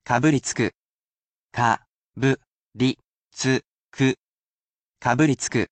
Word of the Week is special in that you do not simply learn a Japanese word or phrase that I will pronounce for you personally, but you can absorb so many other morsels of knowledge.